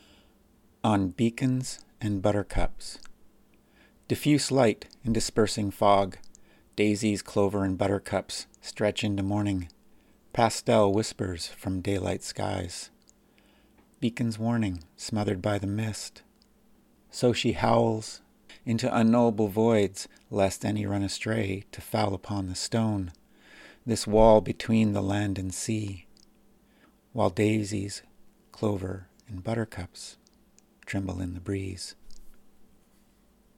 There are two separate readings here, the second includes a recording of a foghorn, much like the one I heard the day I shot this photograph.